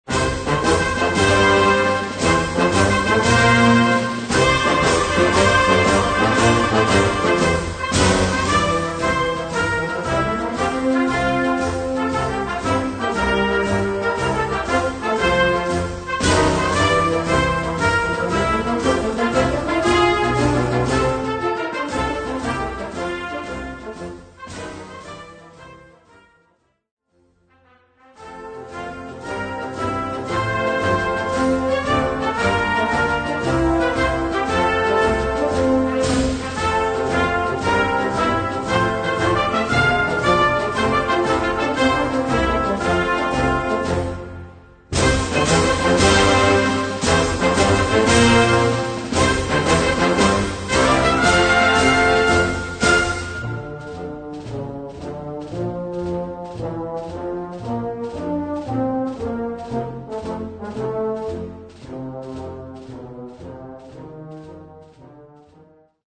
4:00 Minuten Besetzung: Blasorchester Zu hören auf